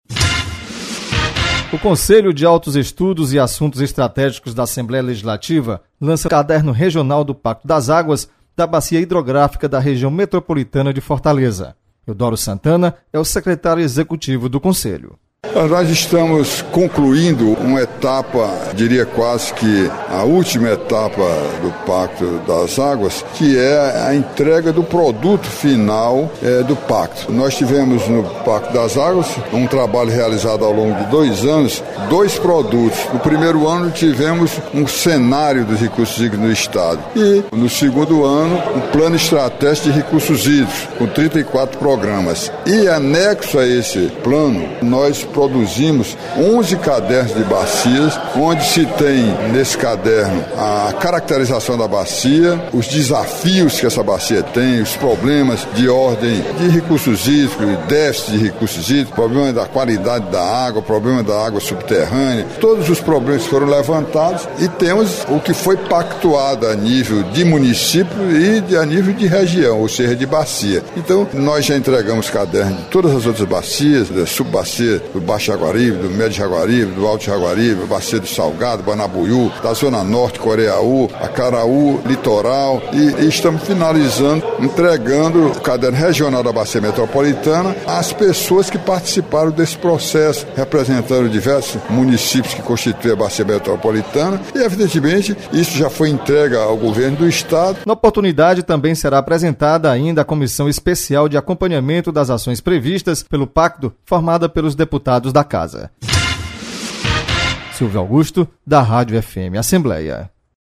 Apresentação do repórter